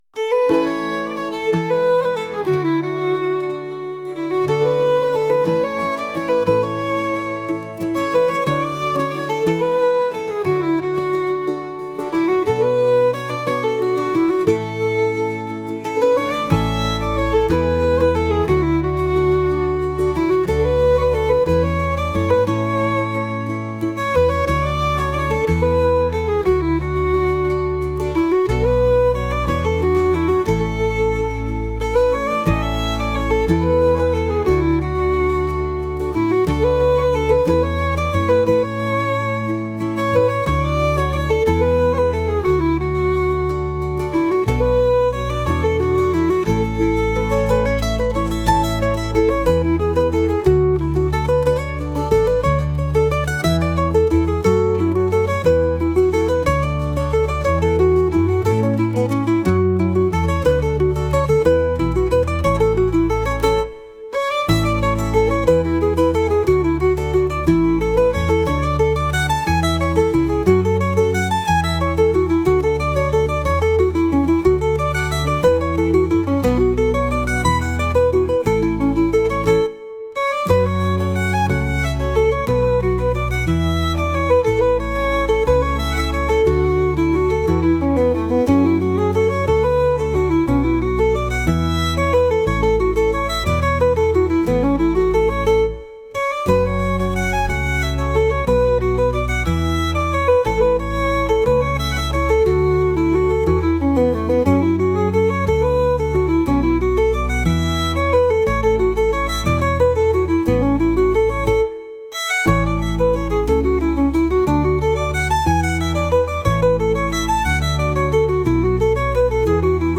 天気のように気分を左右してしまうの君なんだというリコーダー音楽です。